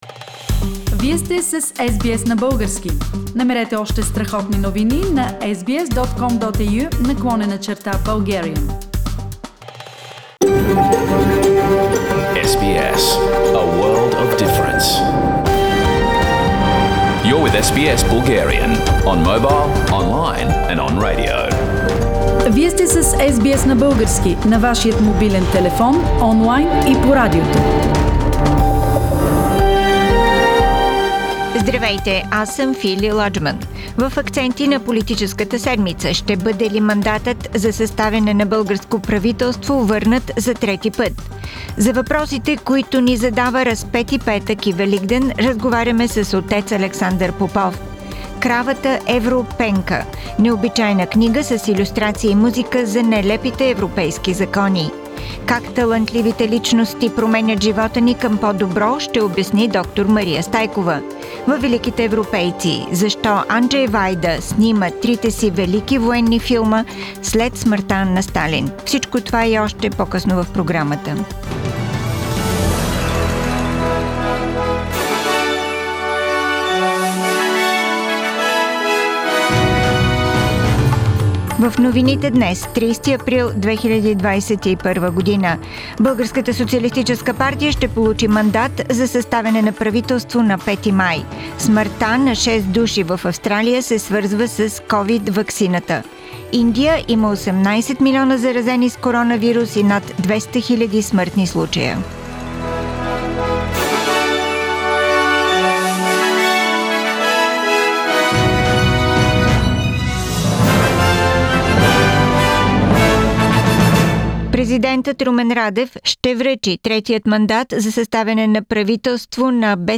Weekly Bulgarian News – 30th April 2021